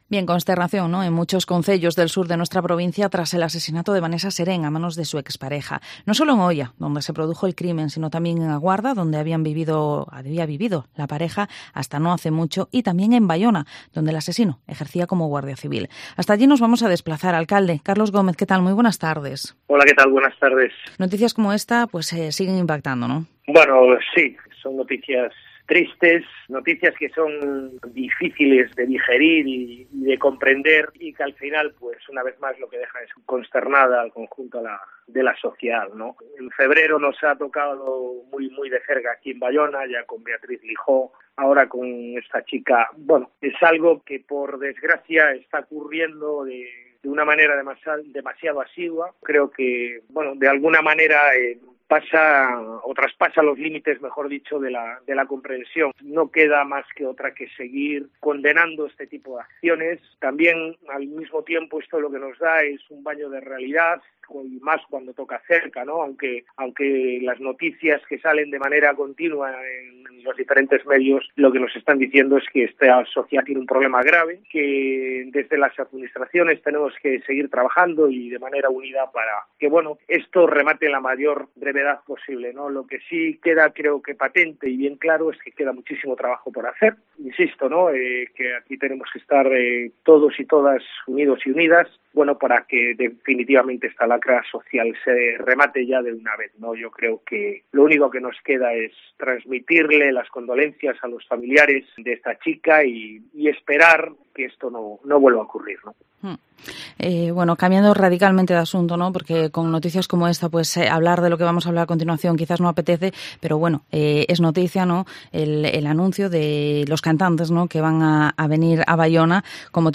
Entrevista al Alcalde en funciones de Baiona, Carlos Gómez